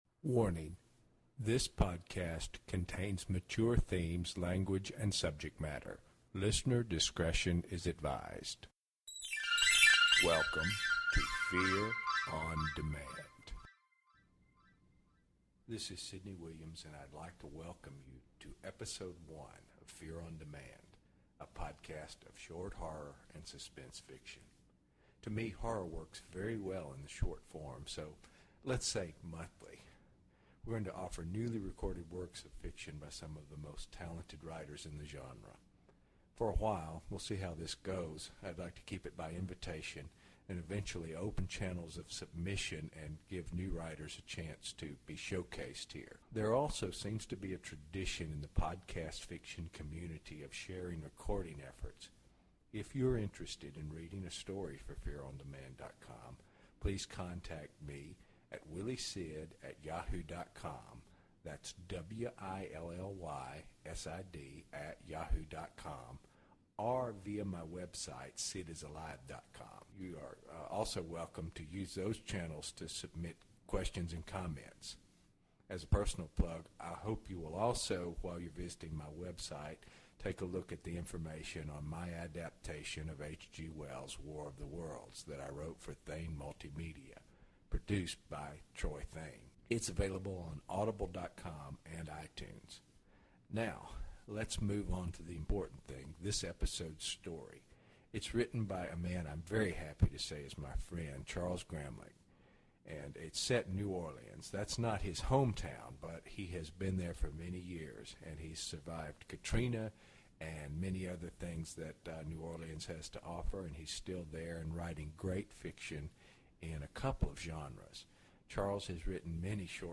Welcome to the beta of Episode 1 of Fear on Demand featuring a short story by Charles A. Gramlich.